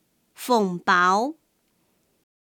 Hakka_tts